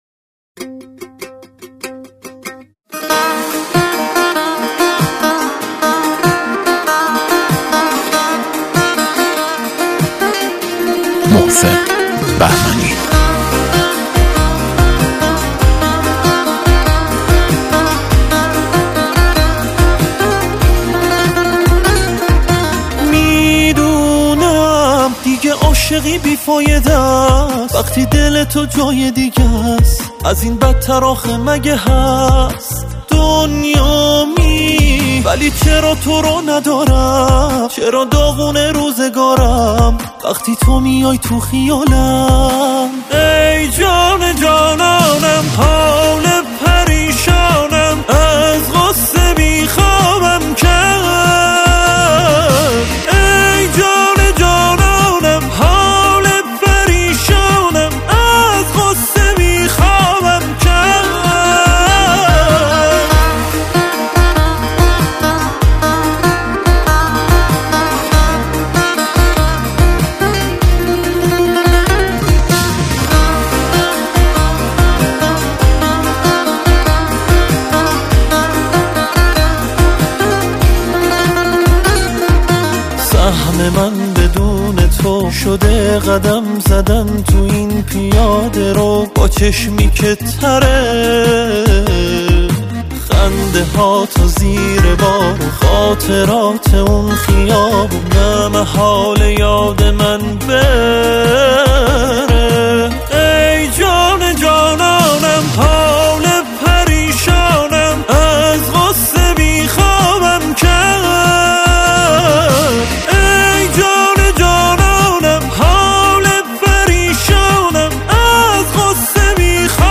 موسیقی پاپ، راک و جز